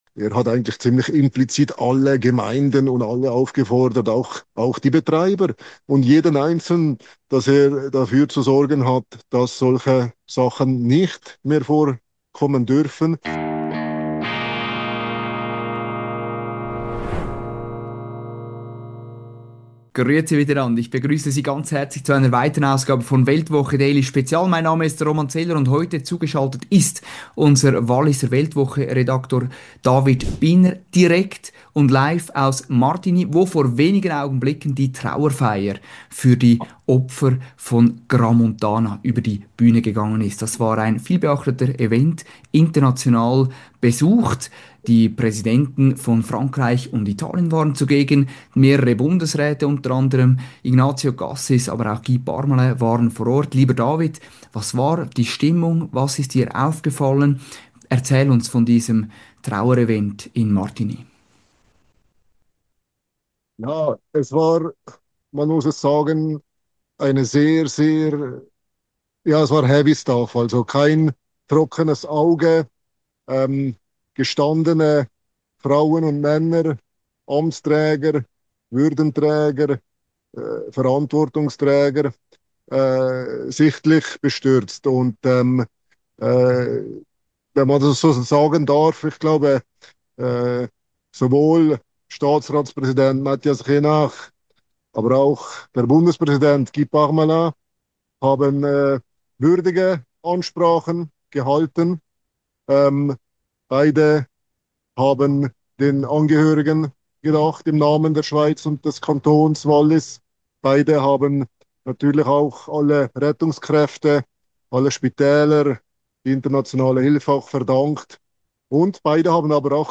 Live aus Martigny